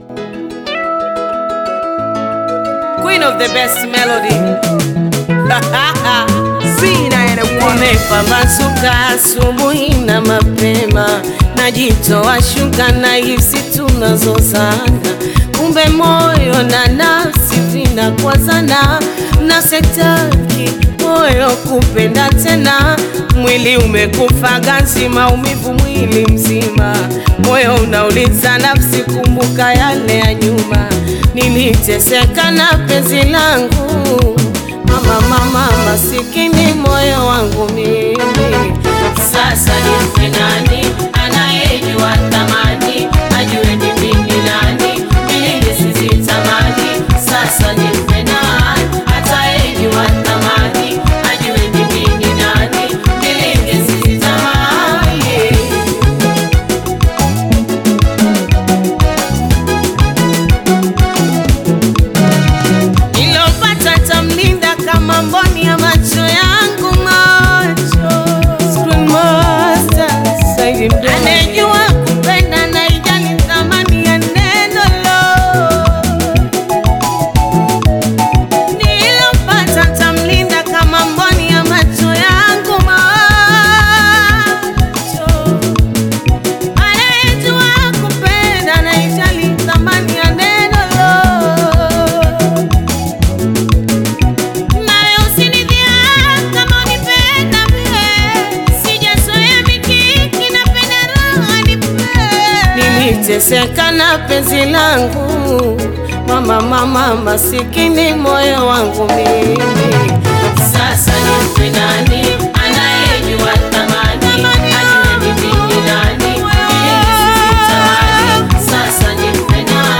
Taarabu